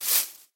grass5.ogg